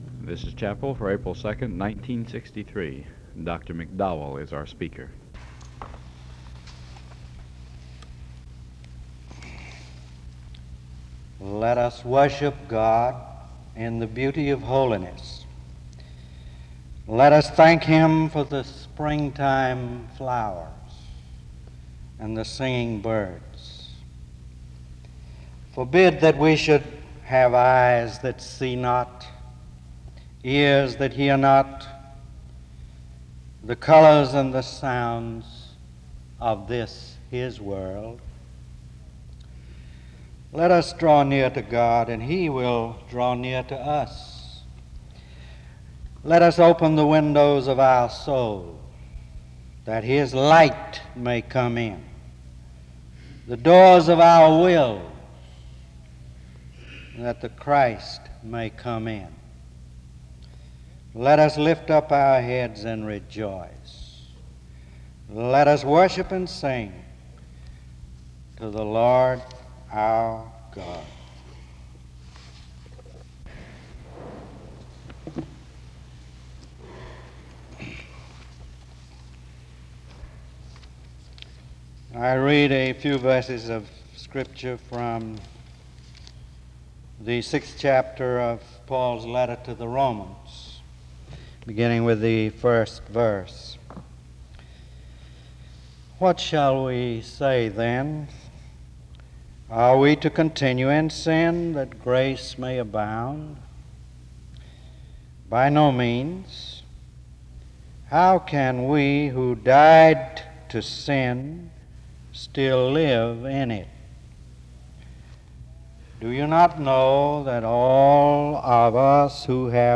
The service begins with the reading of the scripture passage for the sermon and prayer from 0:00-6:26. The scripture for the message was Romans 6:1-7.
SEBTS Chapel and Special Event Recordings SEBTS Chapel and Special Event Recordings